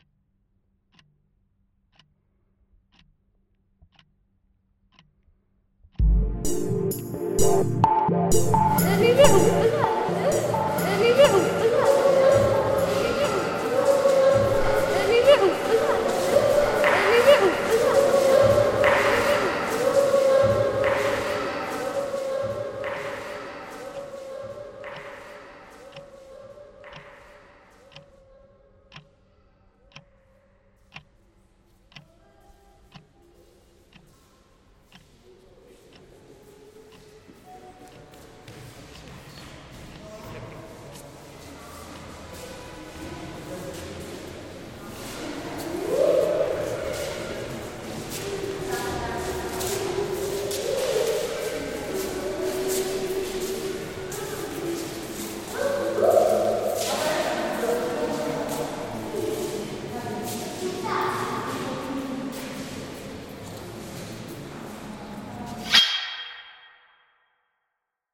memory version of the original recording inside Indian Museum, Calcutta, India